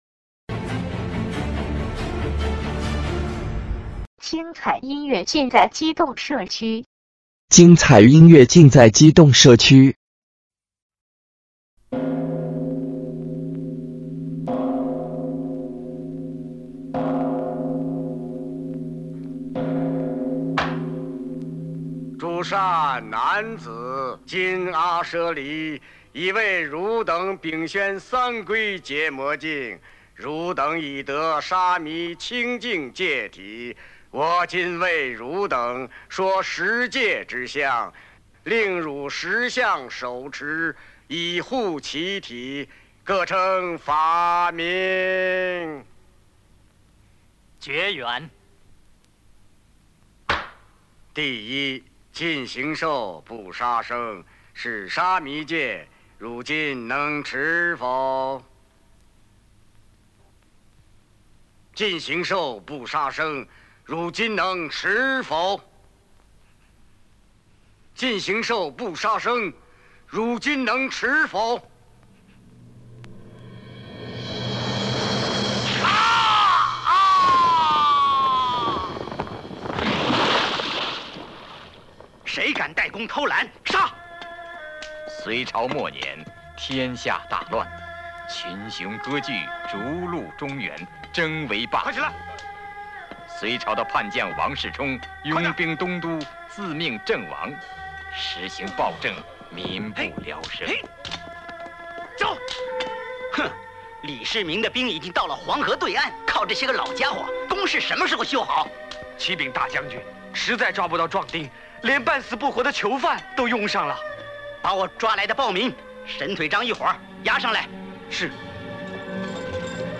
本专辑是1983年日本首版原版黑胶唱片高品质立体声还原音质